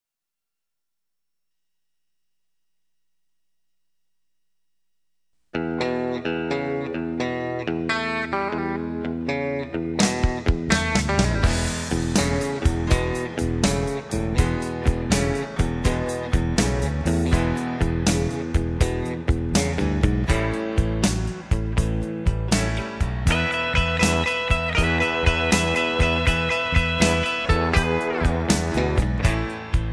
Tags: rock and roll , rock , top 40 , backing tracks